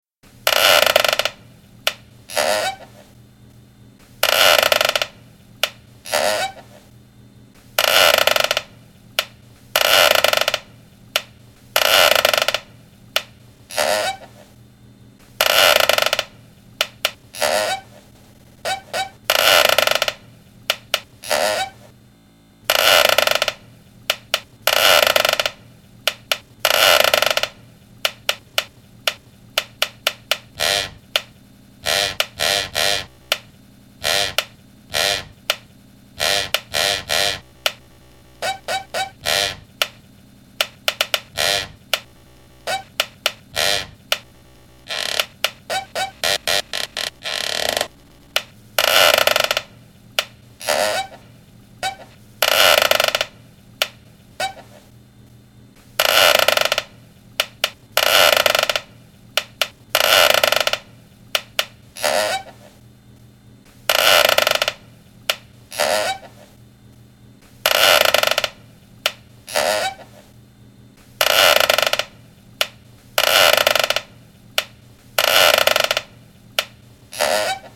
The assignment was to use a single sound and exploit the timbral elements of that to create a 1-2 minute long piece. I chose a squeaking rocking chair.